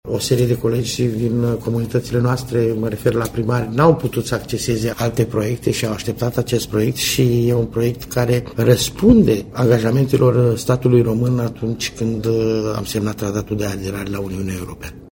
În zonele unde sursele locale nu sunt suficiente, se vor fora puțuri de mare adâncime, spune președintele Consiliului Județean, Ciprian Trifan.